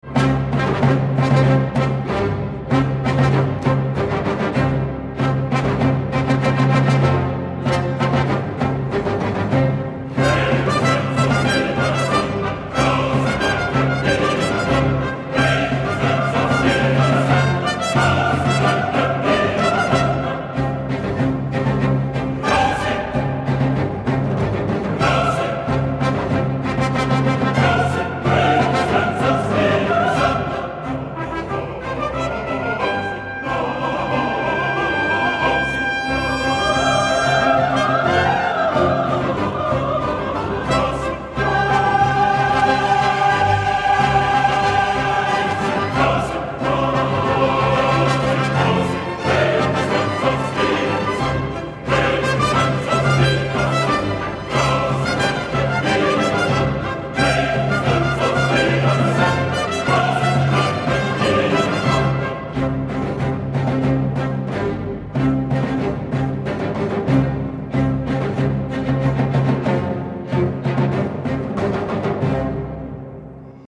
Fanfàrria amb trompetes naturals:
alexandre-fest-fanfare-en-do.mp3